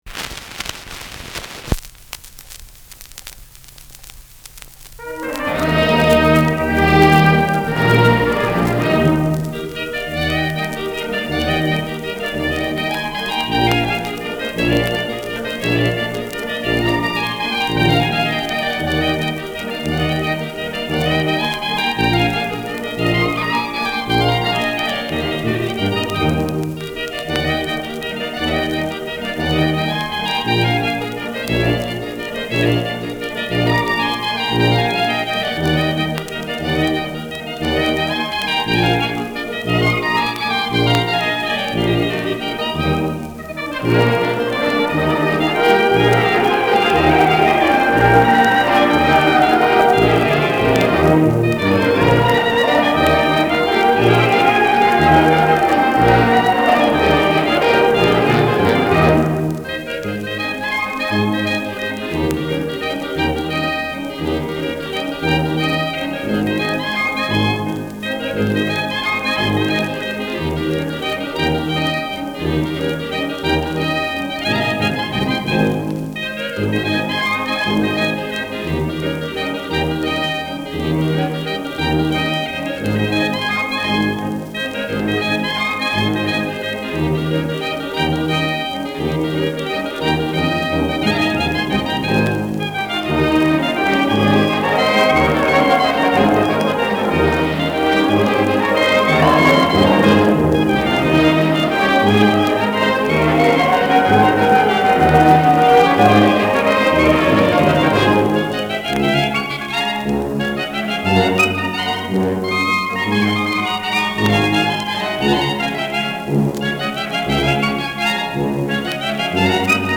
Schellackplatte
Leicht abgespielt : Durchgehend stärkeres Knacken
Große Besetzung mit viel Hall, die einen „symphonischen Klang“ erzeugt. Mit rhythmischem Klatschen.